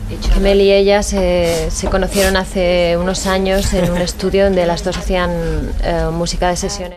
Resposta del grup All Saints, format per Melanie Blatt i Shaznay Lewis, sobre com va ser la seva formació (a l'any 1997 havia publicat el primer disc)
Programa presentat per Tony Aguilar.